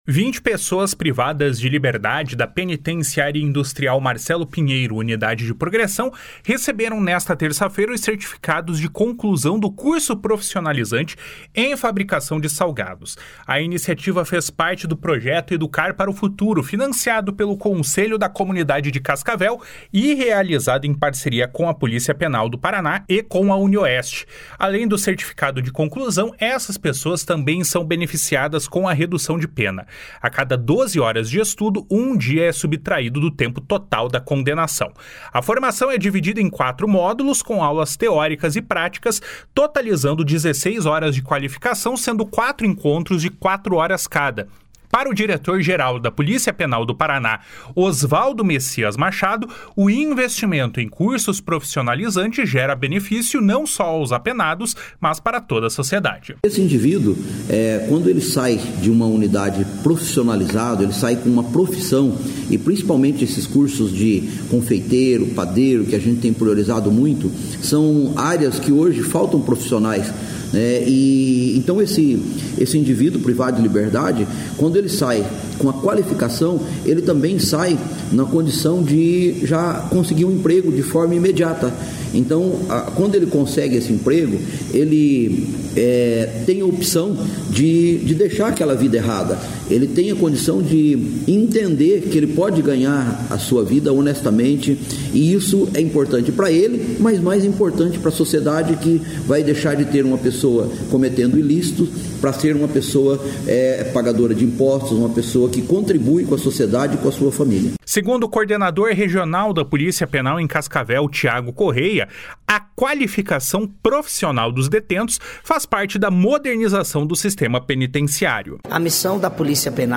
Para o diretor-geral da Polícia Penal do Paraná, Osvaldo Messias Machado, o investimento em cursos profissionalizantes gera benefícios não só aos apenados, mas para toda a sociedade.